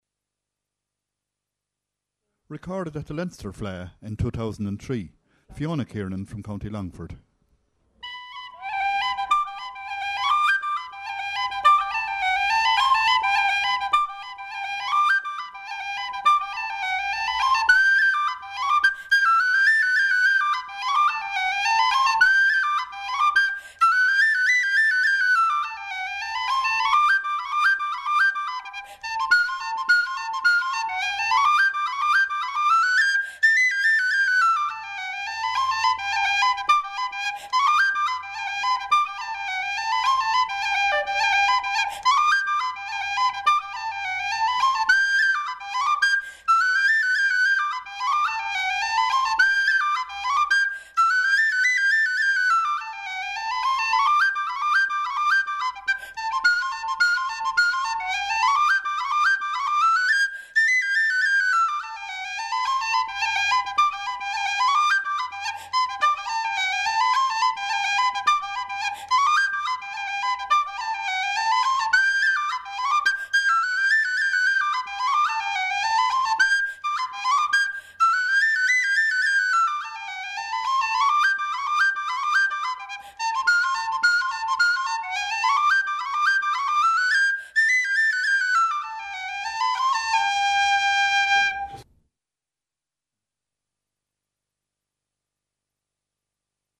Highlights from Fleadh Cheoil na hÉireann 2011 in Cavan.